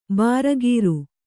♪ bāragīru